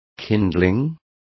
Also find out how fajina is pronounced correctly.